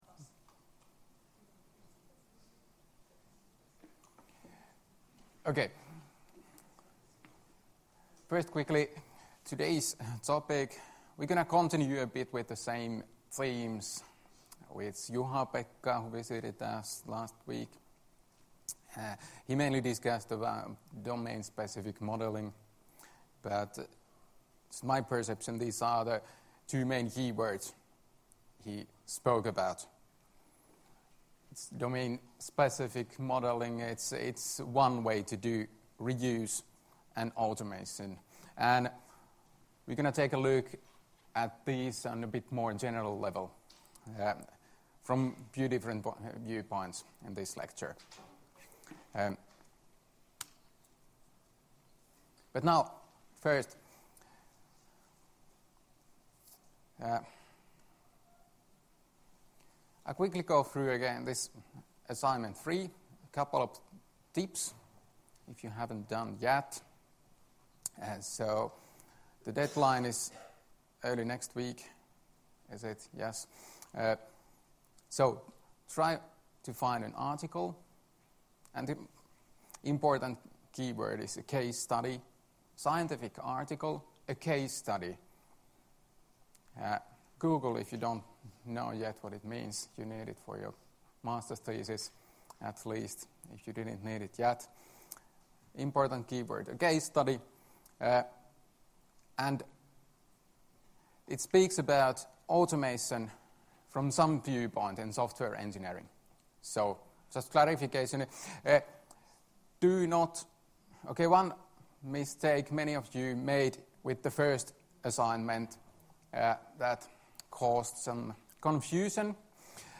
Luento 15.2.2018 — Moniviestin